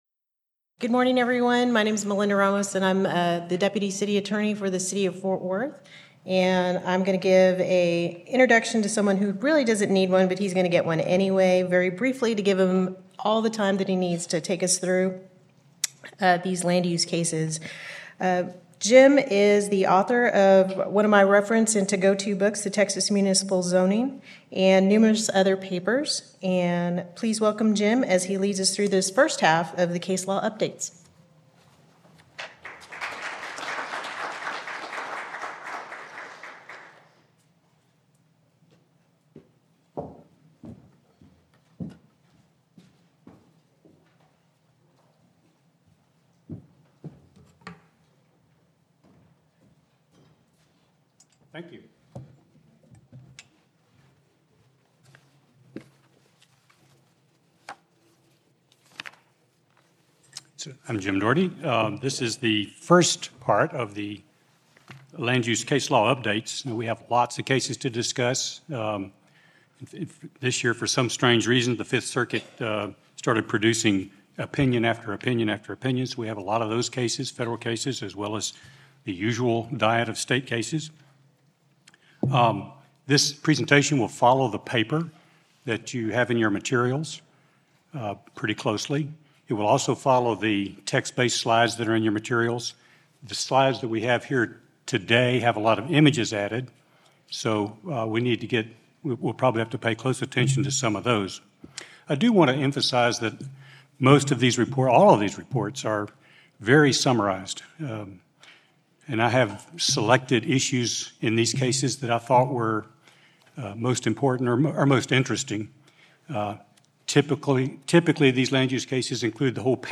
Originally presented: Apr 2024 Land Use Conference